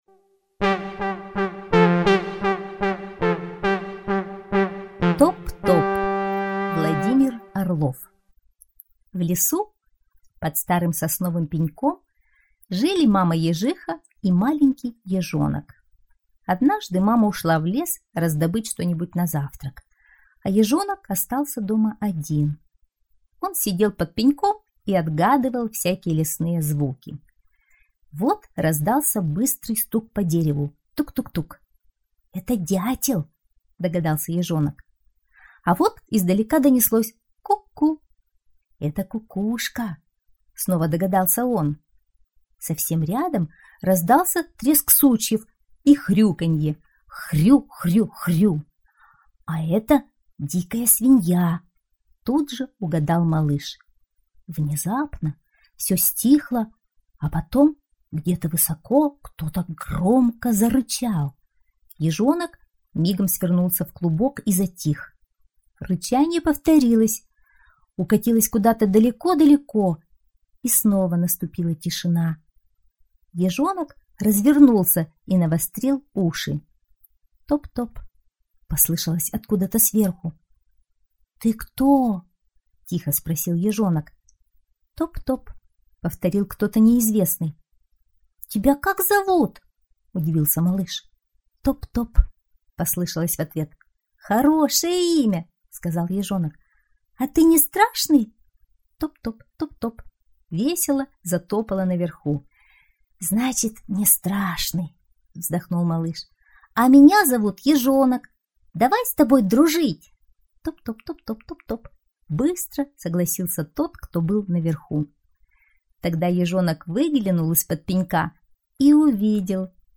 Топ-топ - аудиосказка Орлова - слушать онлайн